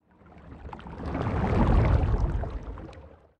Sfx_creature_glowwhale_swim_fast_06.ogg